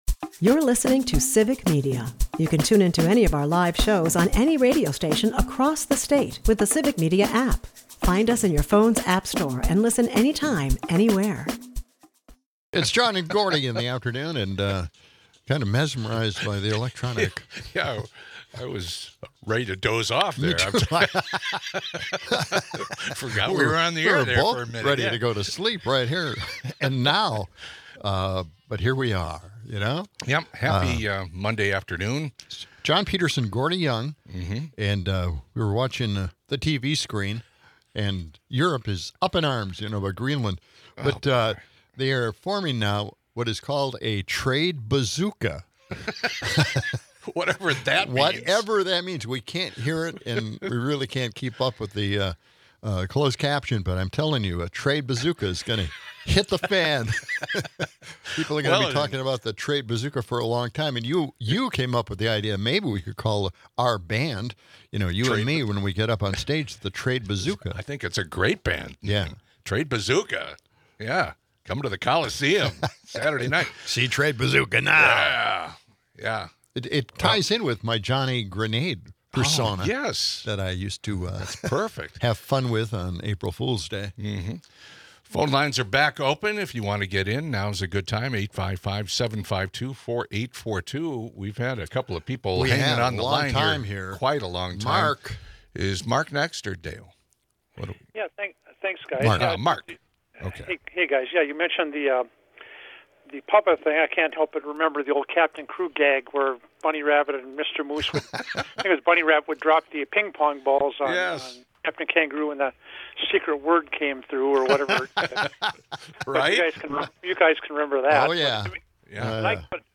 After giving more updates on the Minneapolis occupation, the guys take some time to celebrate Dr. Martin Luther King, Jr. Day. We listen to part of one of his speeches about staying hopeful, which is something we must not forget today.